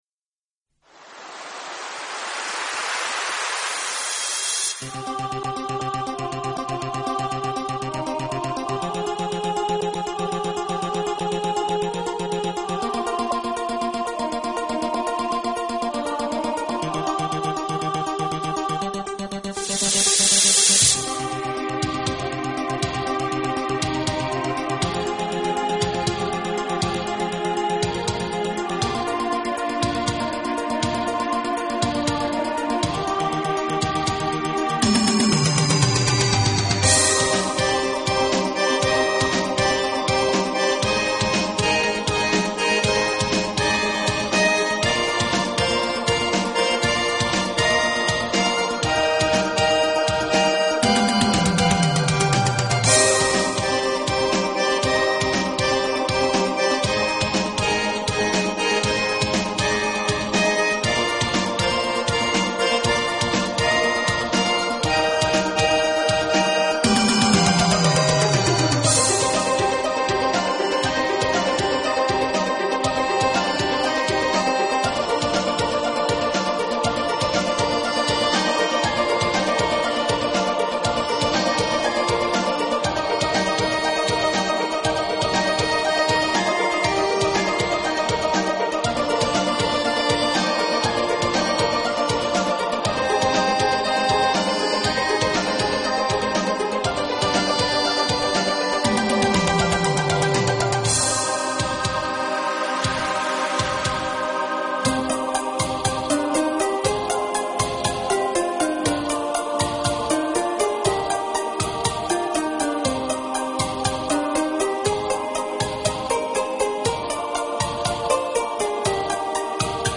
PRIX MODERNE SOLO/DUO